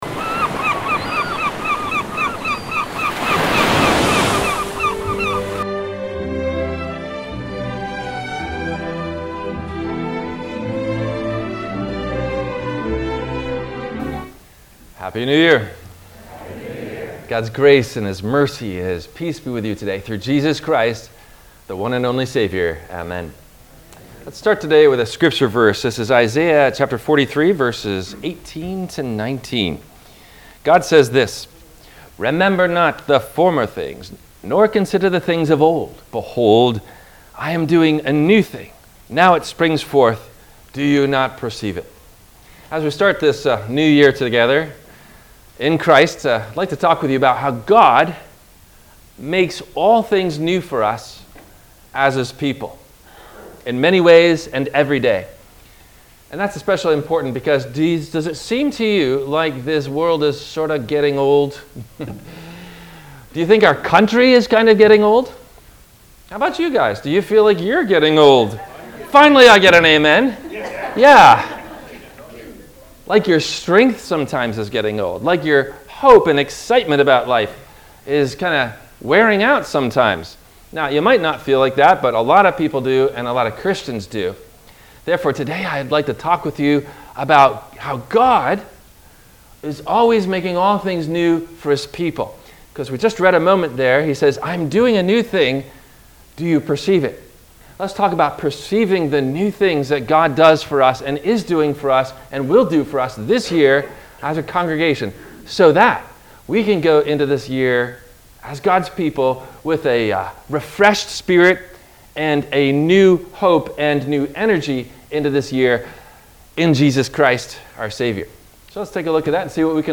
Does God Really Make All Things New For Us? – WMIE Radio Sermon – January 15 2024